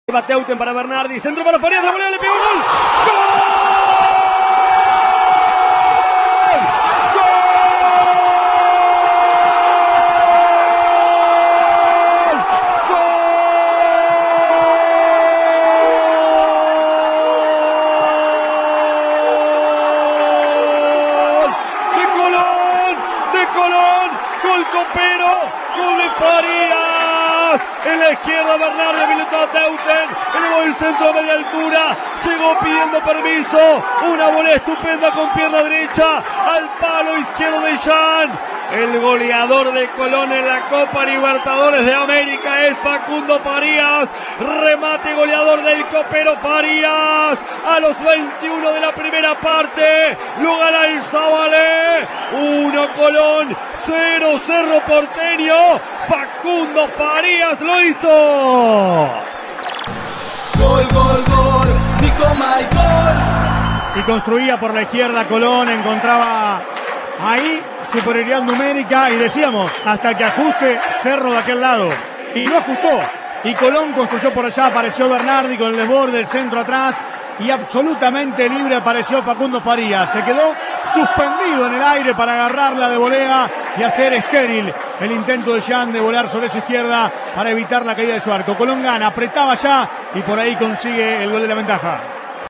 Fue en el estadio Brigadier Estanislao López de Santa Fe.
Transmitió Cadena 3 Santa Fe